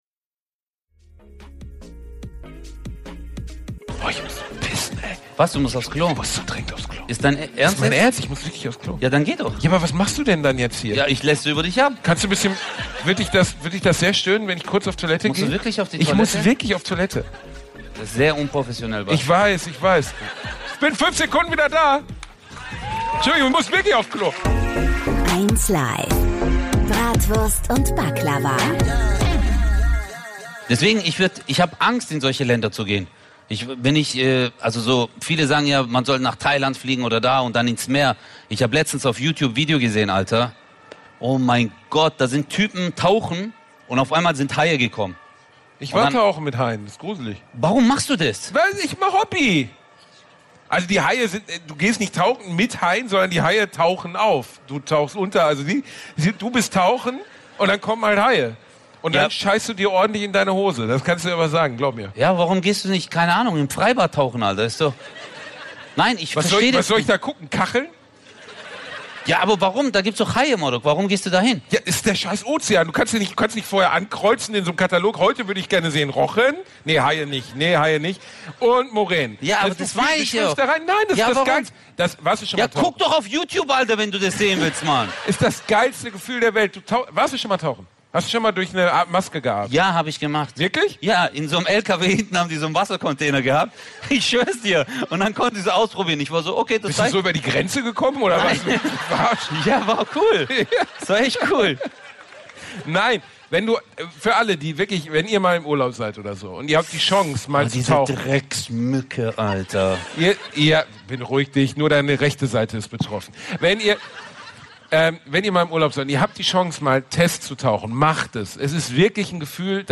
#59 Kein Safeword - live in Dortmund Teil 2 ~ Bratwurst und Baklava - mit Özcan Cosar und Bastian Bielendorfer Podcast